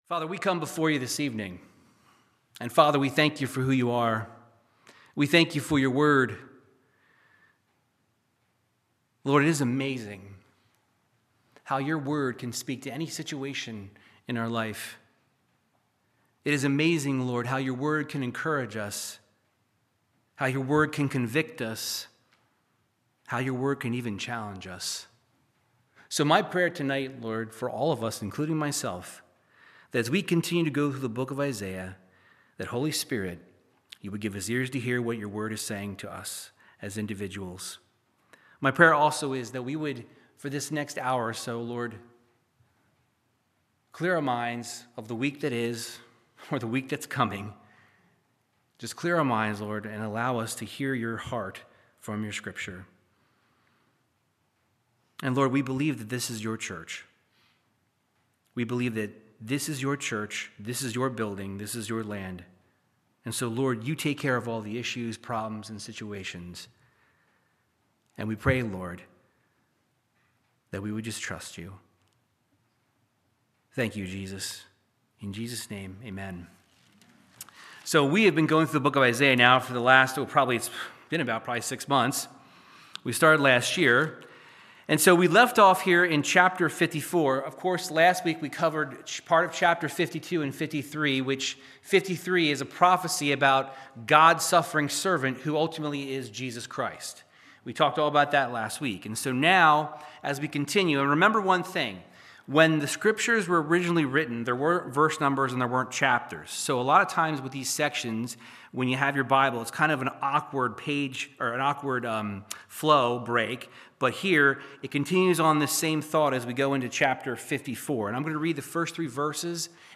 Verse by verse Bible teaching in Isaiah chapters 54 and 55